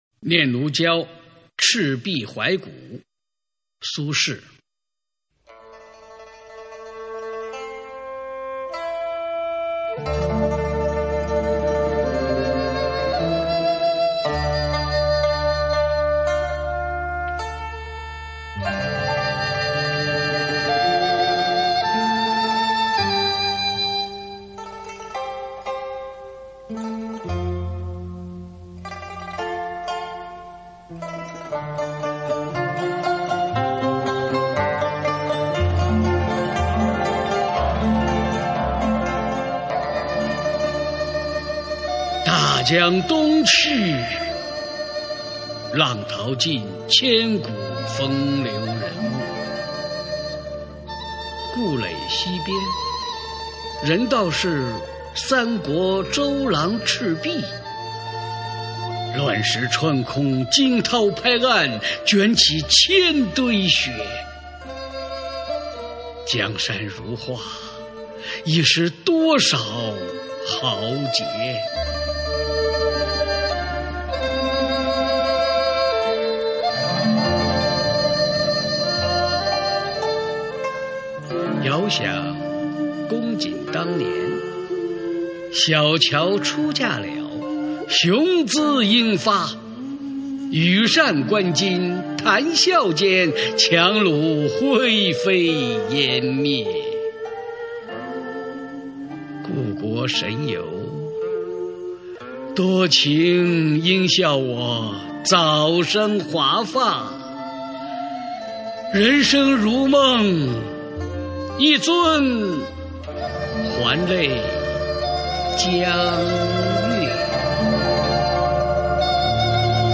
首页 视听 经典朗诵欣赏 群星璀璨：中国古诗词标准朗读（41首）